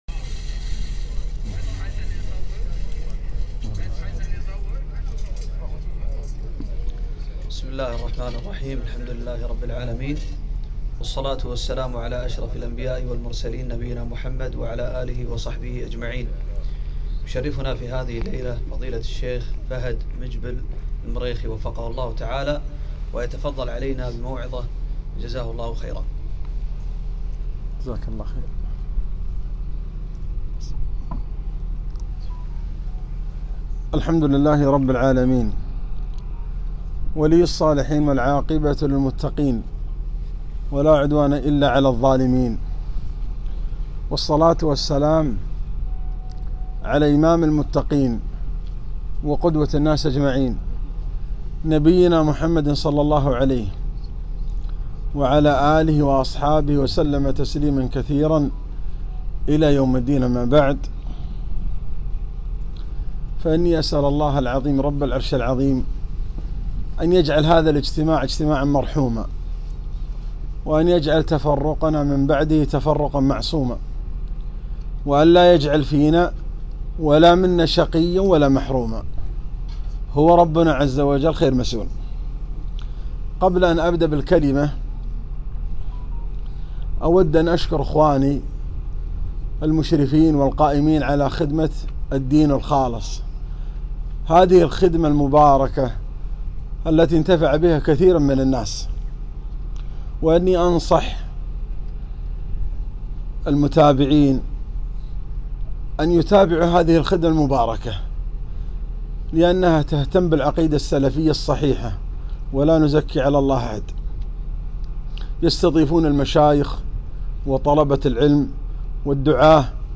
محاضرة - اللحظات الأخيرة من خروج الإنسان من هذه الدنيا